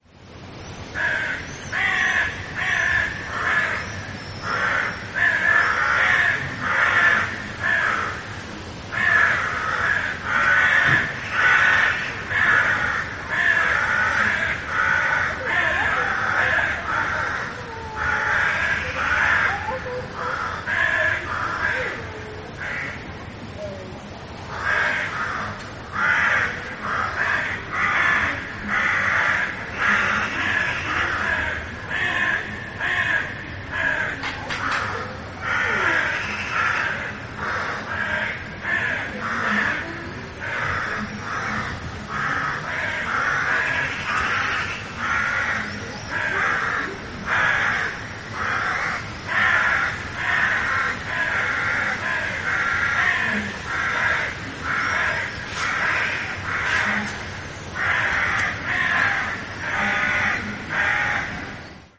Die Vögel haben viel zu sagen in den letzten Tagen.